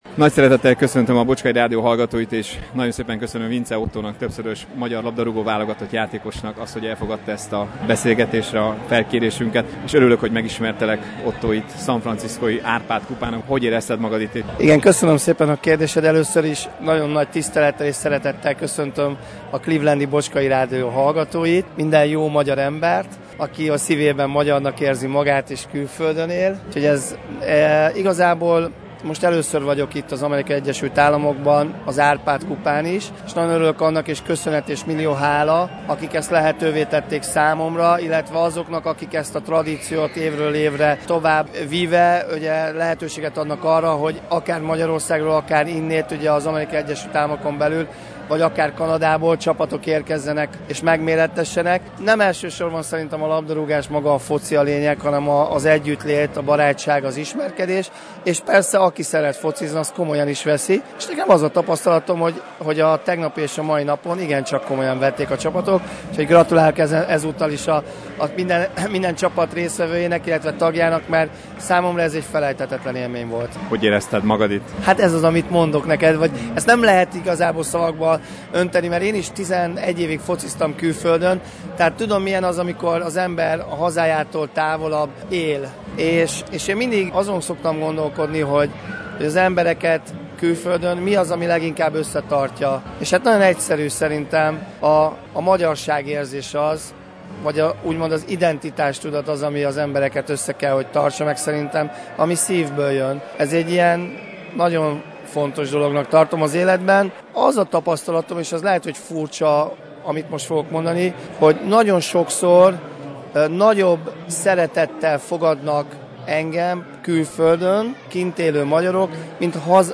(Az interjú itt is meghallgatható a vasárnapi rádióadásunk után).